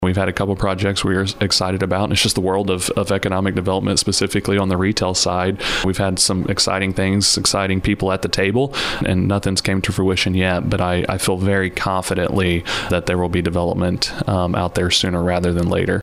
City Administrator of Park Hills, Zach Franklin, says the ultimate goal is to get business in the area just off Highway 67.